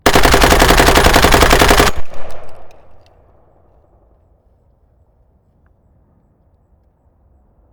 На этой странице собраны звуки турели — от механизма поворота до залповой стрельбы.
Звук выстрела пулемета с турельной установки